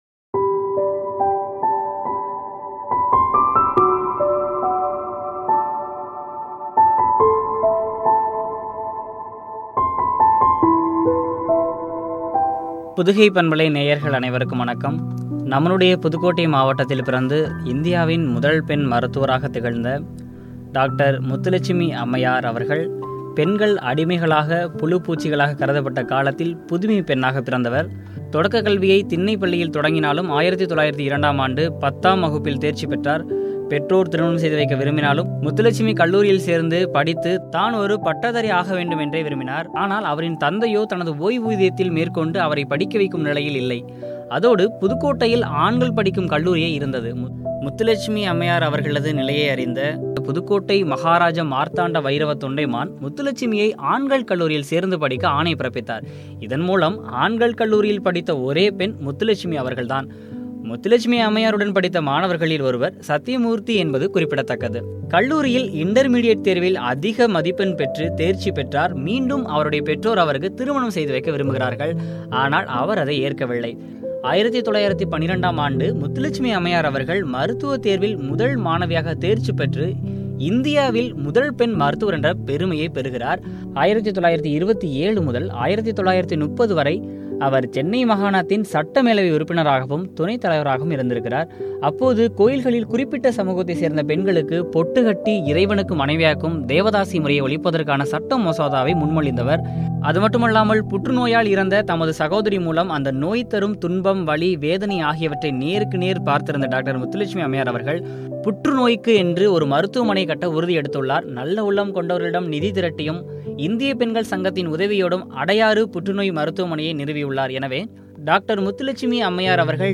முத்துலட்சுமி அம்மையாரின் வாழ்க்கை வரலாறும், சமூகத்தொண்டும் பற்றிய உரையாடல்.